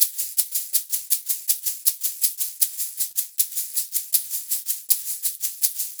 80 SHAK 08.wav